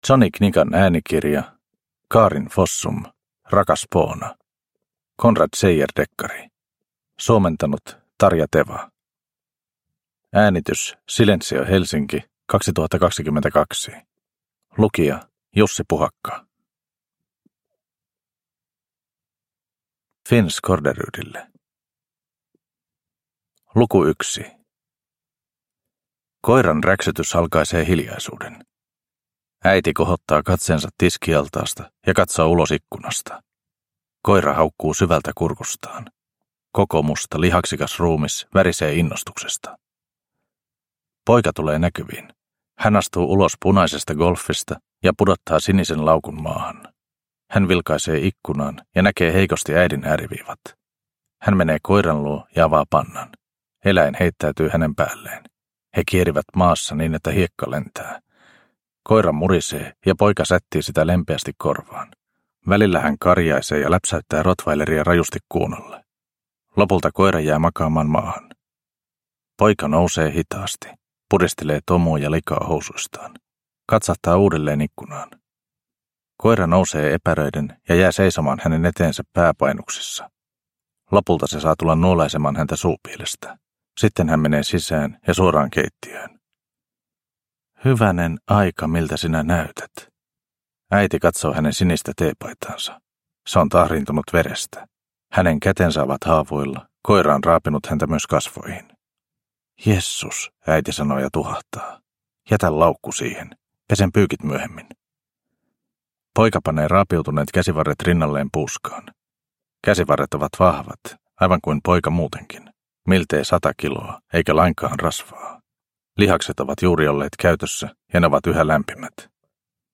Rakas Poona – Ljudbok – Laddas ner